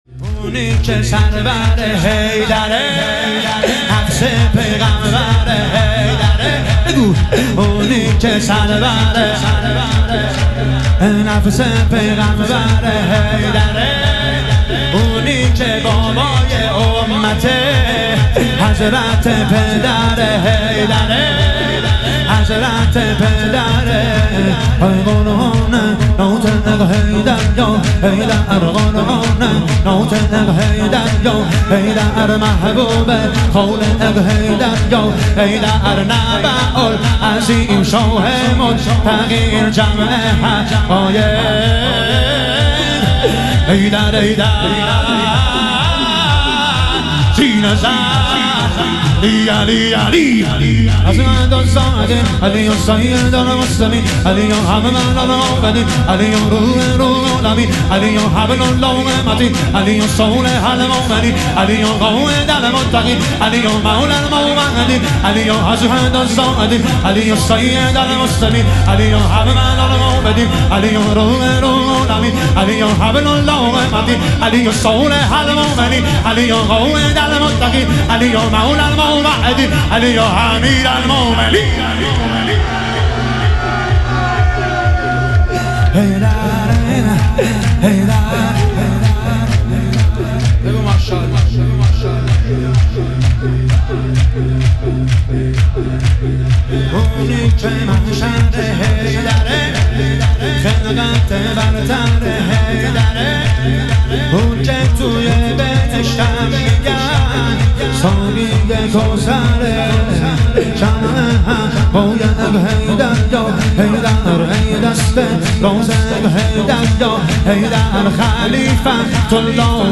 شهادت امام جواد علیه السلام - شور